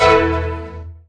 coin01.mp3